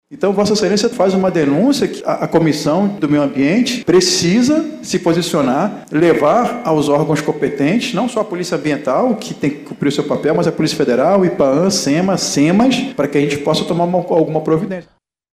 Sonora-Peixoto-vereador-1.mp3